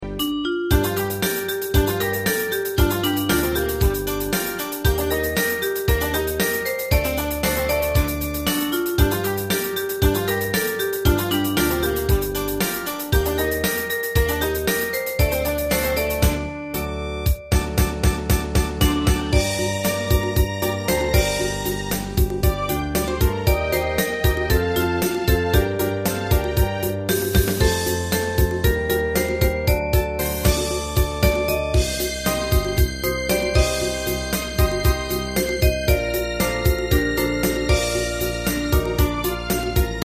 大正琴の「楽譜、練習用の音」データのセットをダウンロードで『すぐに』お届け！
Ensemble musical score and practice for data.